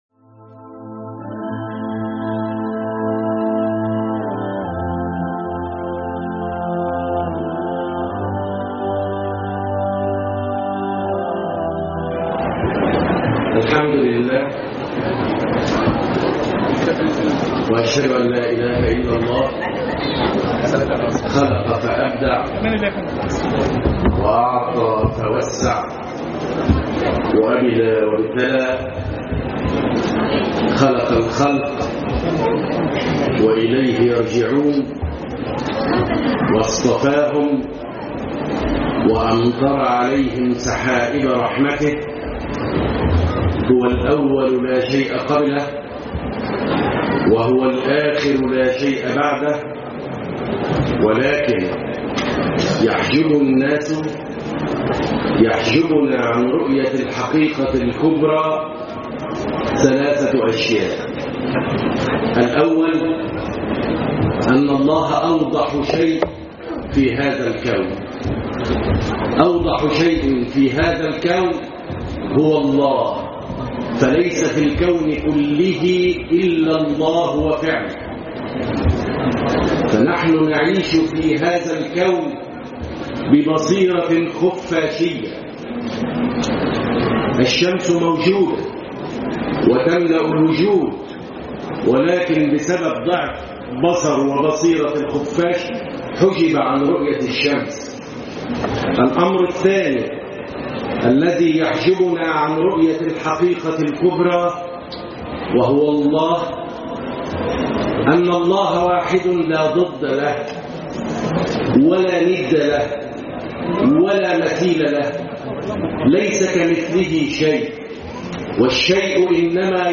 محاضرة
بالمؤتمر السنوى الحادى عشر للإعجاز العلمي للقرآن الكريم